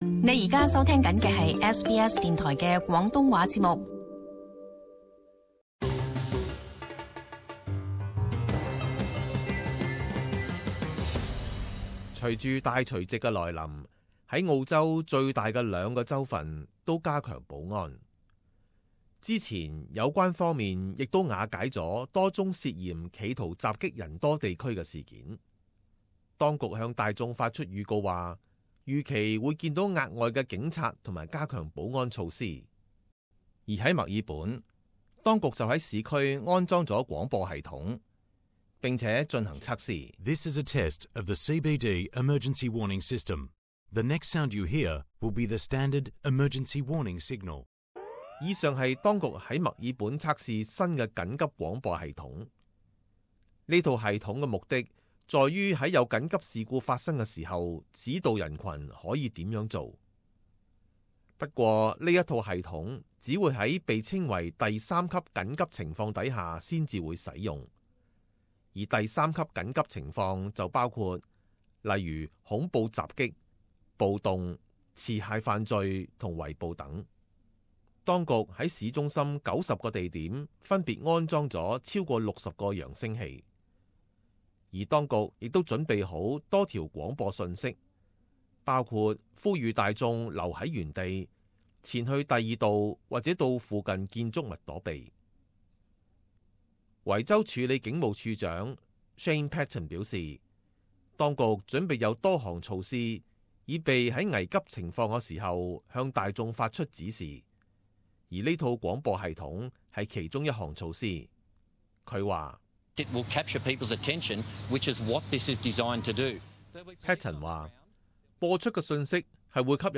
【時事報道】雪梨墨爾本大除夕加强保安